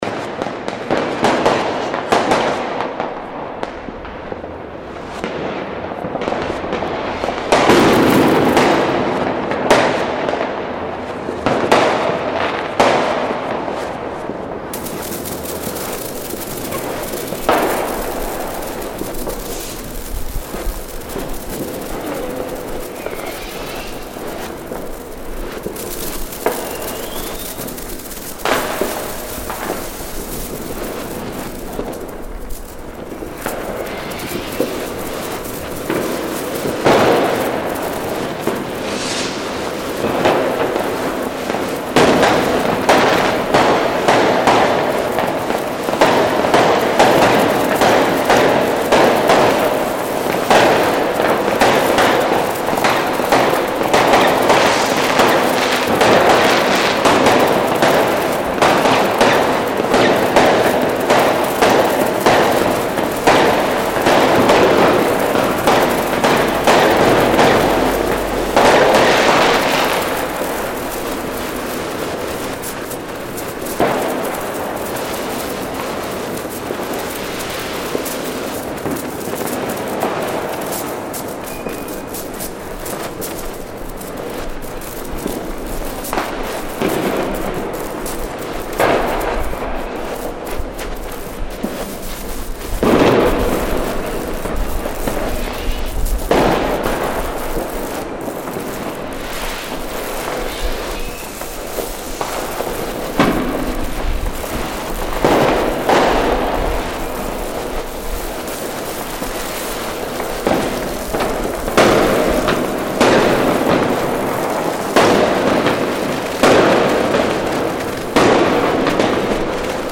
2024 New Year's Eve soundscape from Siemensdamm, Östlicher Luftfilterturm in Berlin, Germany.